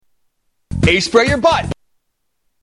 Tags: Media Doc Bottoms Aspray Doc Bottoms Aspray Ads Doc Bottoms Aspray Commercial Body Deodorant